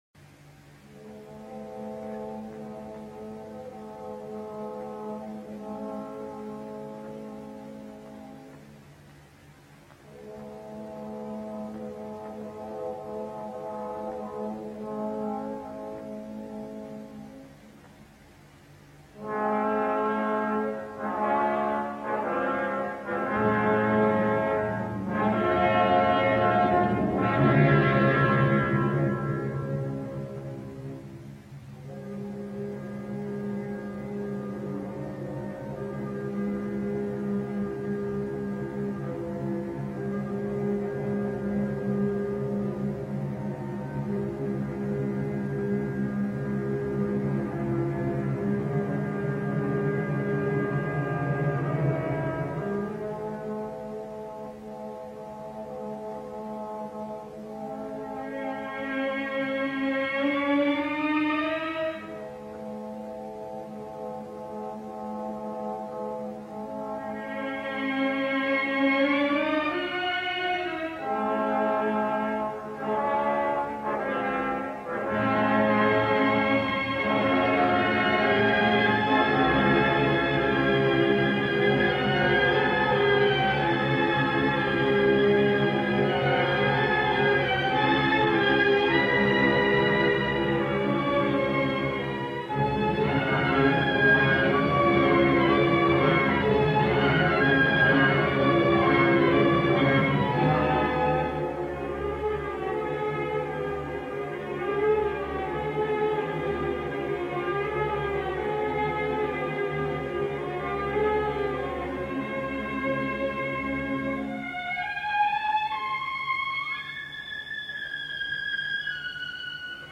il paradigma della voce d’oro all’italiana, quella del soprano lirico
Di certo con queste signore, connotate da rigogliosa salute vocale (una salute che è frutto certamente della natura, ma anche di un’emissione e di una disciplina music